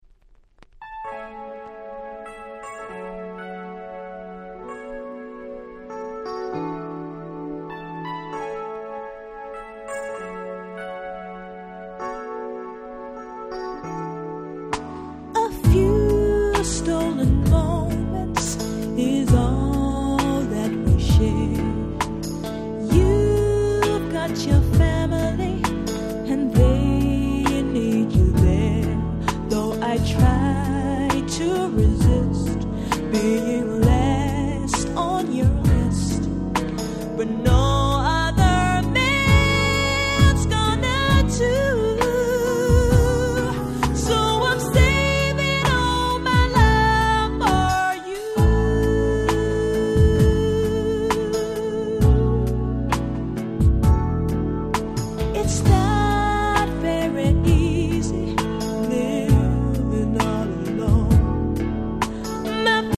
02' Nice R&B !!